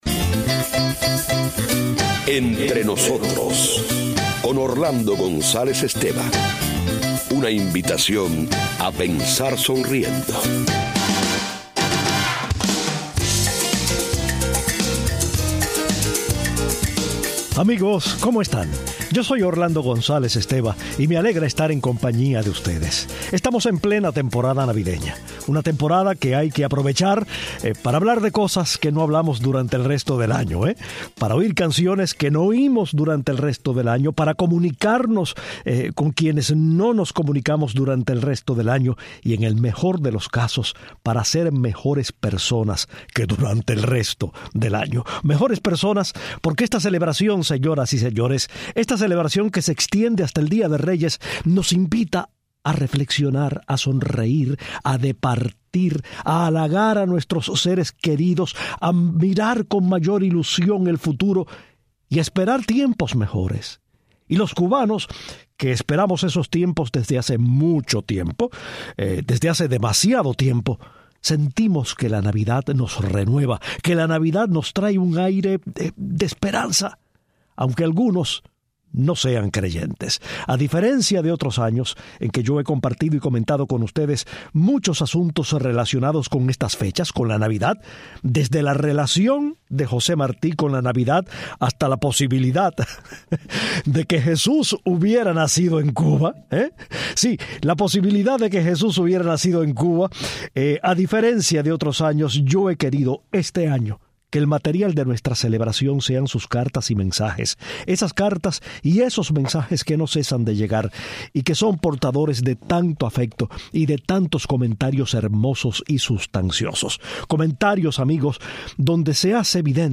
Continuamos recibiendo cartas y atendiendo llamadas de oyentes que desean felicitarnos por fín de año.